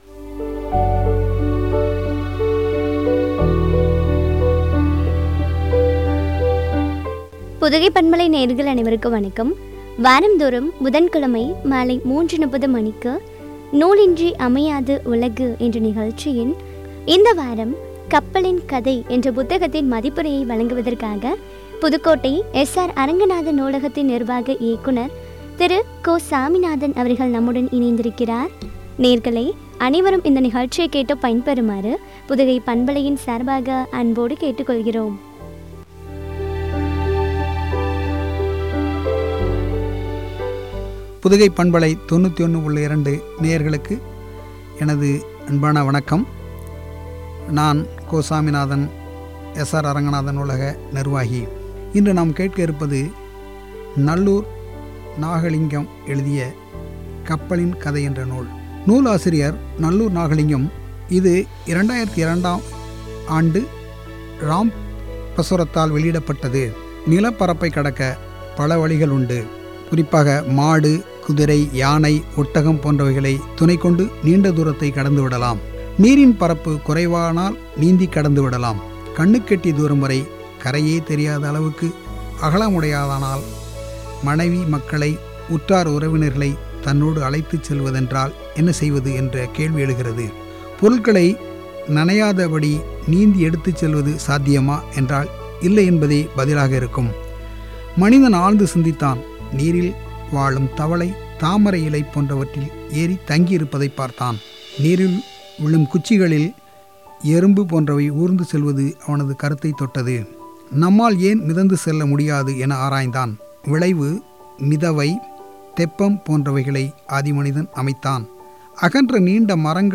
குறித்து வழங்கிய உரையாடல்.